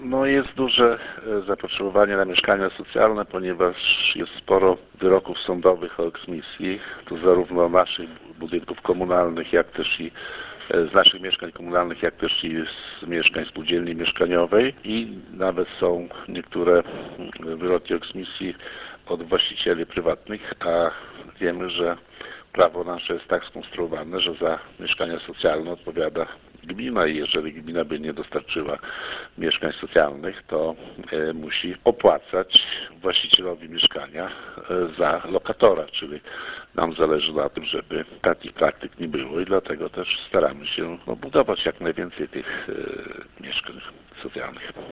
Burmistrz Olszewski przyznaje, że zapotrzebowanie na mieszkania socjalne w gminie jest bardzo duże.